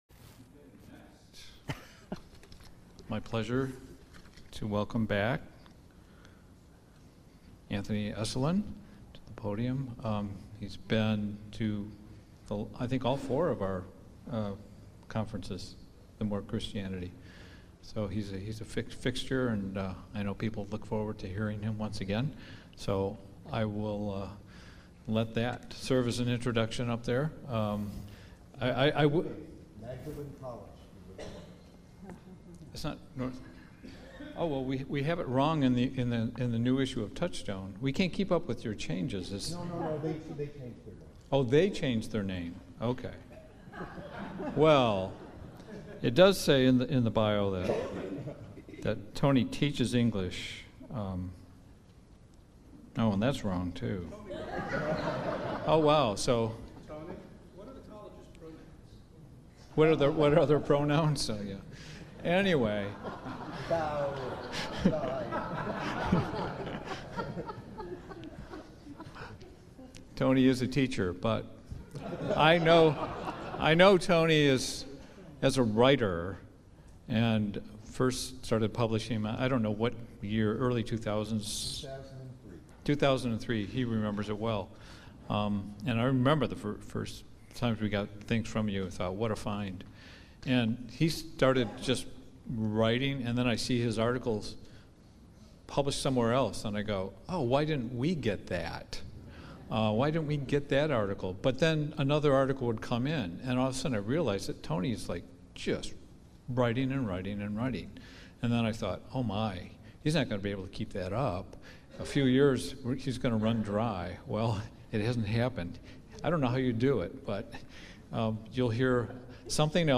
Duration: 39:49 — Talk delivered on Thursday, October 10, 2019